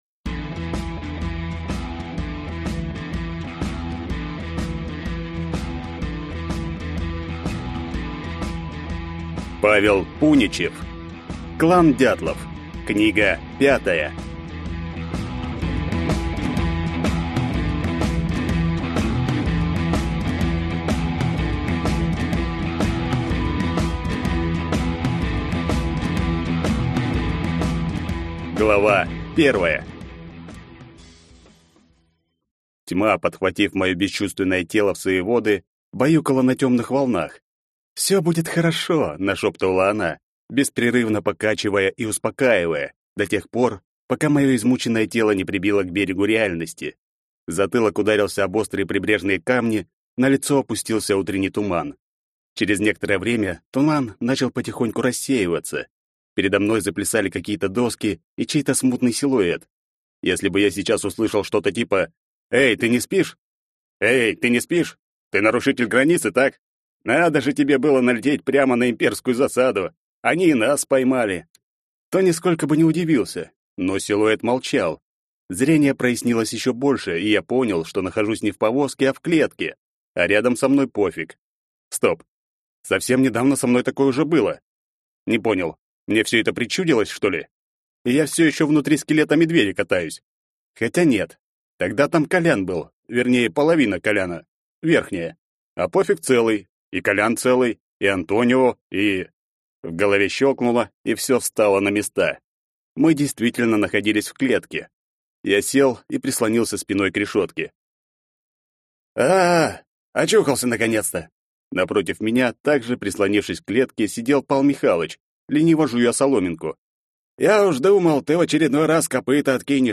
Аудиокнига Клан «Дятлов». Книга 5 | Библиотека аудиокниг